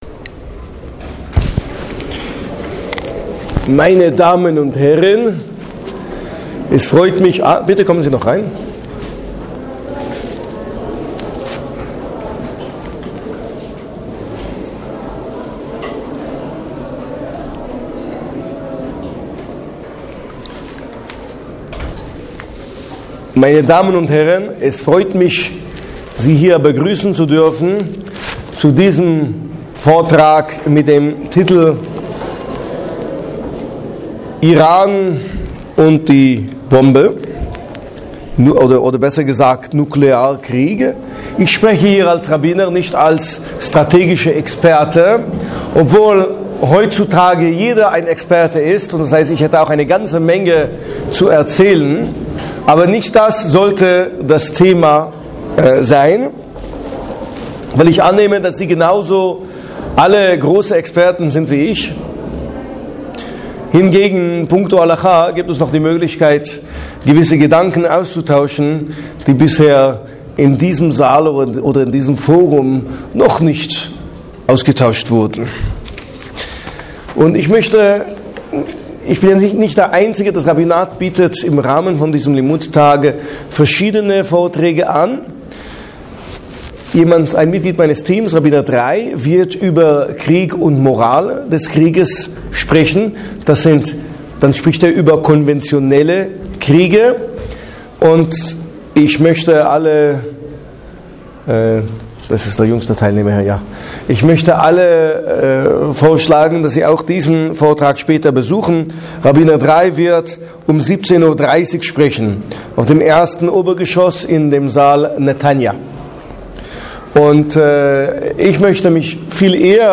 Ist der Einsatz von Massenvernichtigungswaffen in einem Konflikt je nachvollziehbar, aus jüdischer Sicht? Darf ein Land nach dem jüdischen Religionsgesetz Massenvernichtigungswaffen in seinem Arsenal aufnehemen? Dieser Vortrag wurde im Rahmen des Angebotes des Rabbinates der IKG München an der Limmud München 2012 Veranstaltung vermittelt.